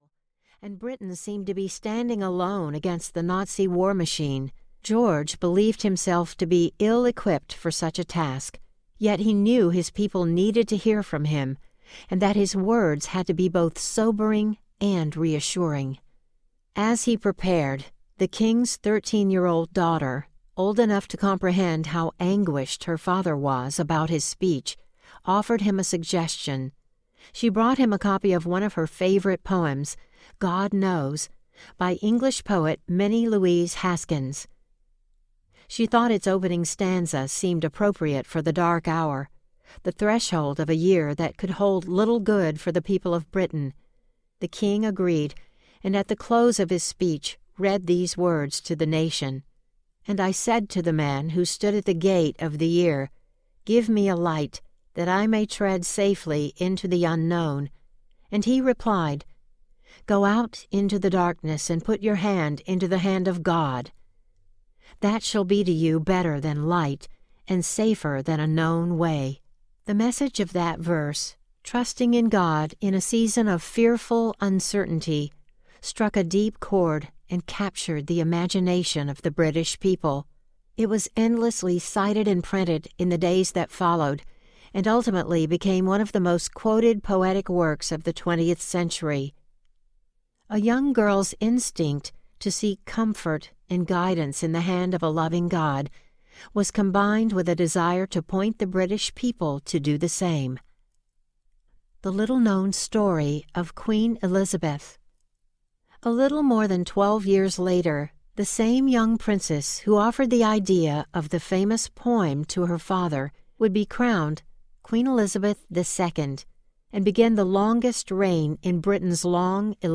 Only One Life Audiobook